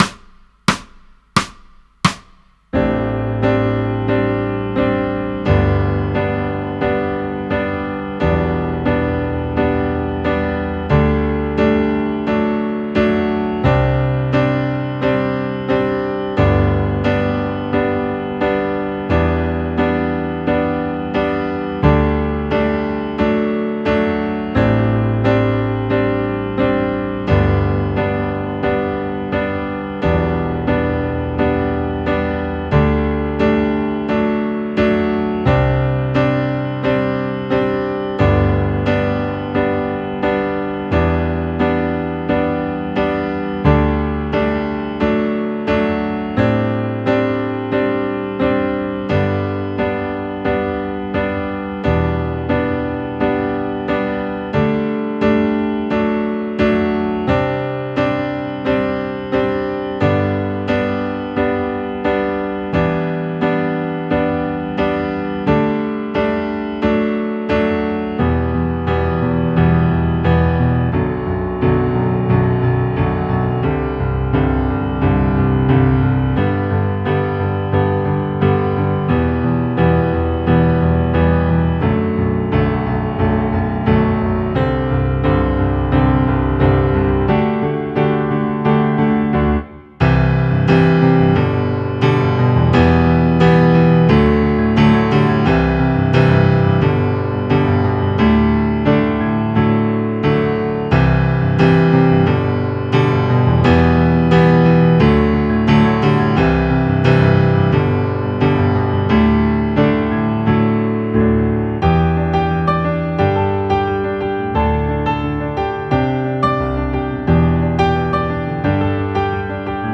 ■OFF VOCAL